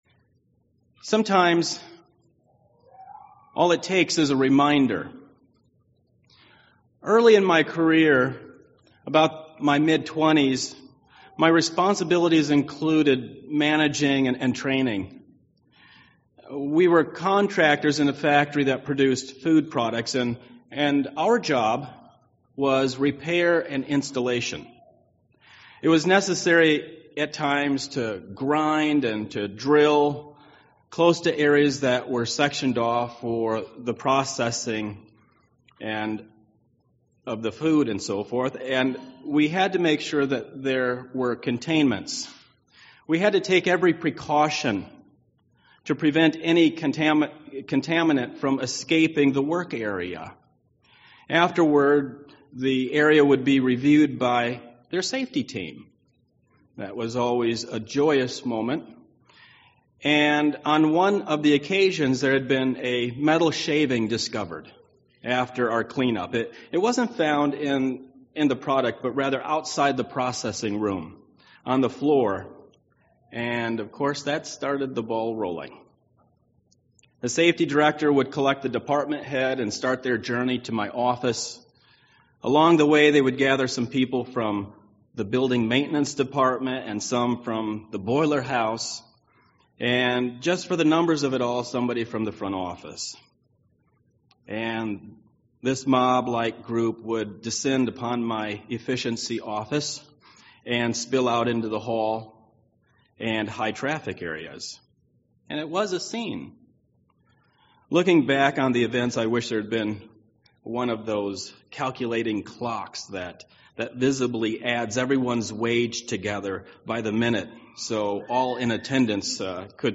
Sermons
Given in Milwaukee, WI